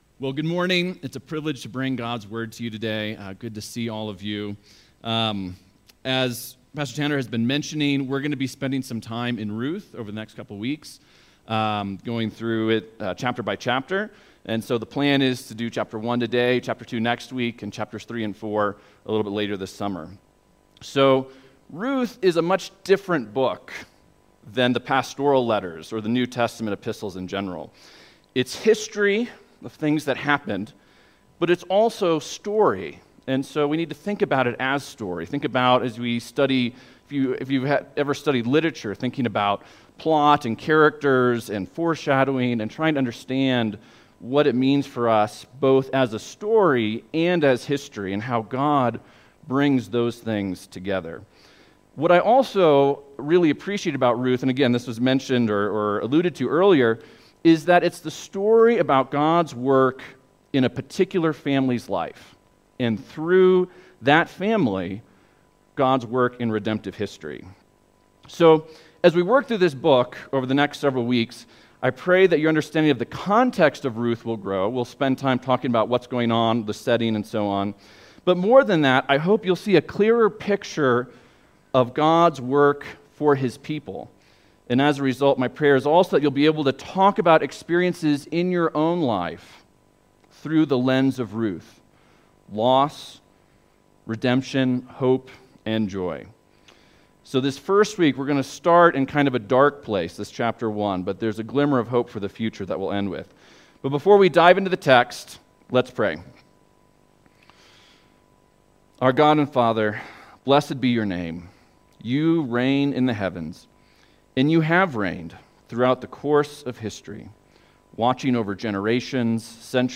Sermons | First Baptist Church of Leadville